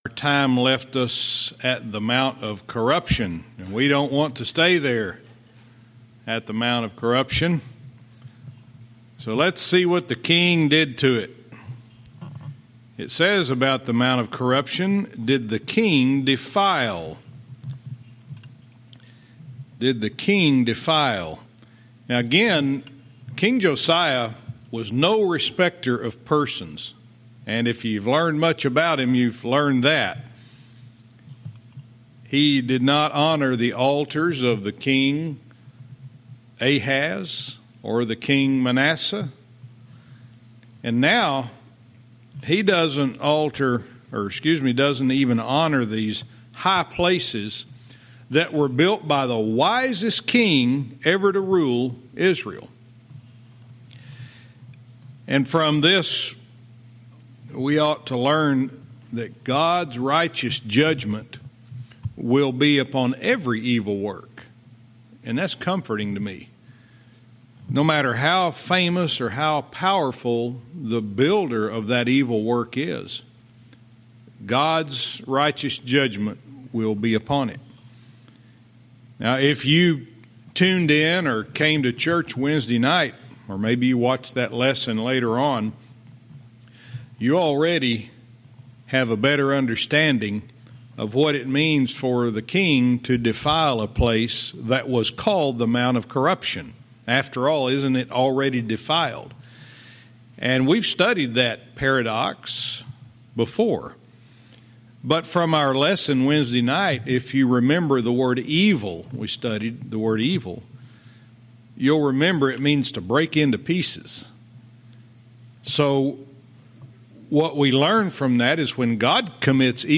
Verse by verse teaching - 2 Kings 23:14-20